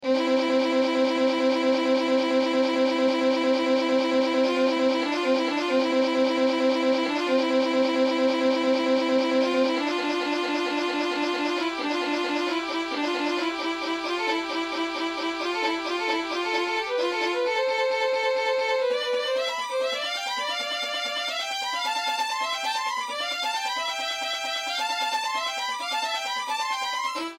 Sonificação usando o twotone, com os seguintes parâmetros: